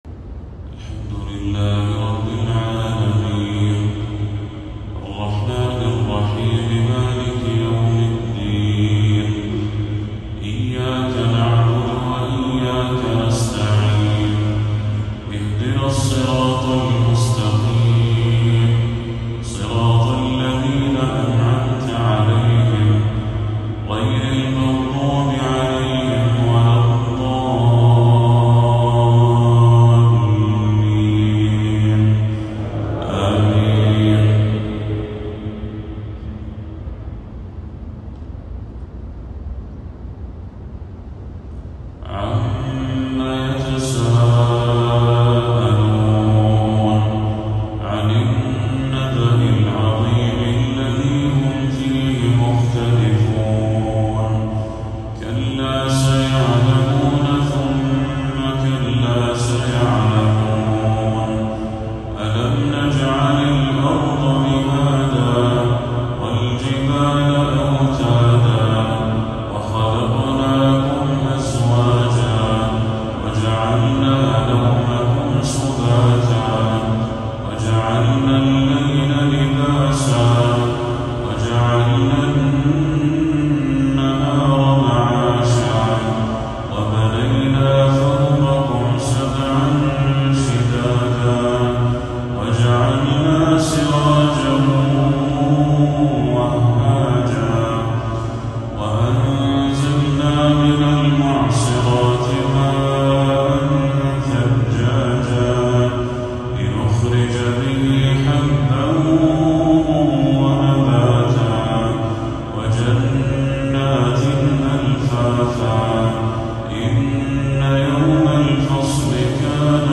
تلاوة حجازية لسورة النبأ للشيخ بدر التركي | عشاء 23 صفر 1446هـ > 1446هـ > تلاوات الشيخ بدر التركي > المزيد - تلاوات الحرمين